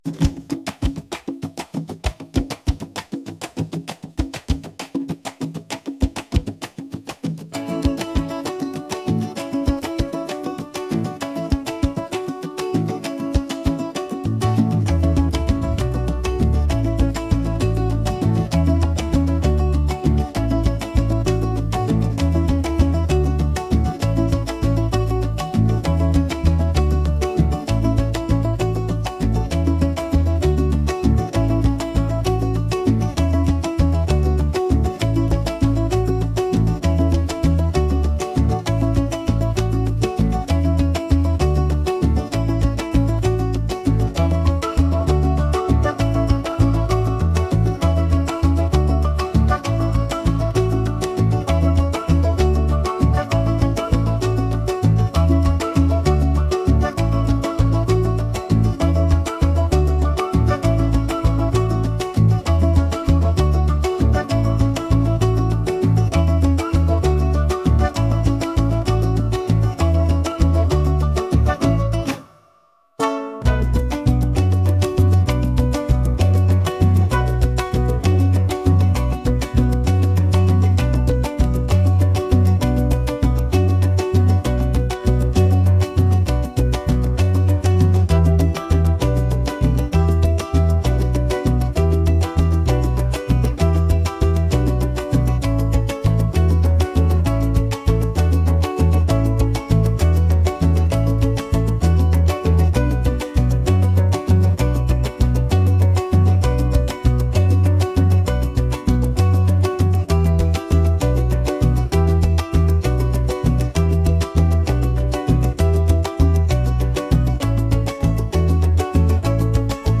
latin | energetic